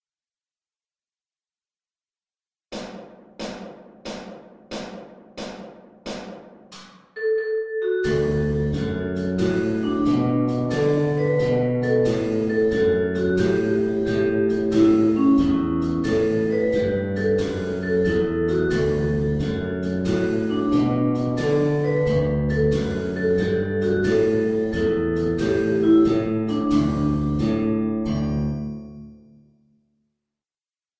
"He's Got the Whole World in His Hands" melody track